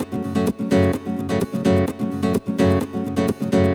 VEH3 Nylon Guitar Kit 2 - 20 F# min.wav